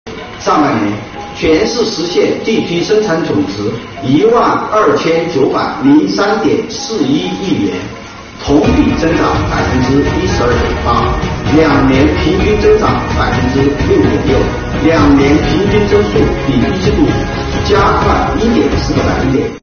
7月19日，重庆市政府新闻办举行2021年上半年重庆市经济运行情况新闻发布会，介绍了有关情况。